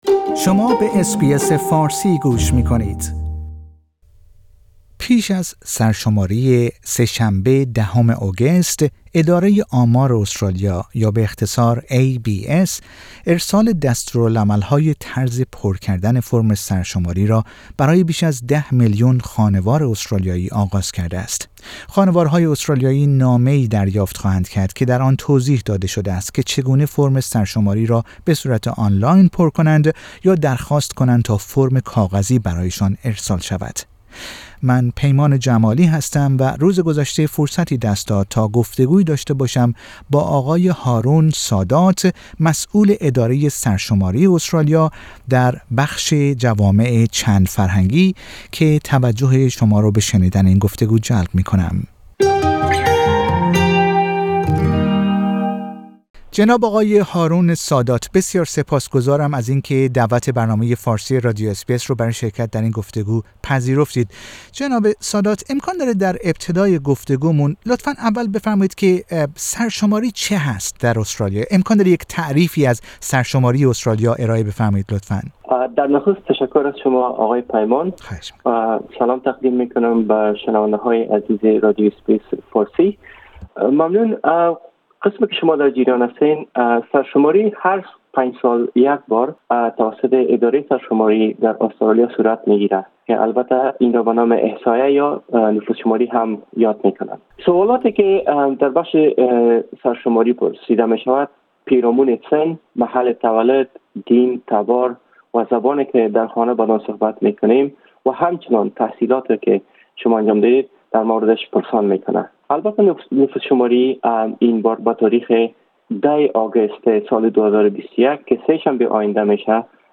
در گفتگو با رادیو اس بی اس فارسی درباره آنچه باید درباره سرشماری سال ۲۰۲۱ استرالیا بدانید، سخن می گوید.